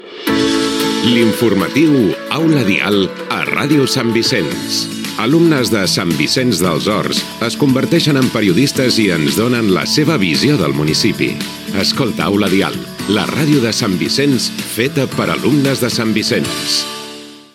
Promoció del programa.
FM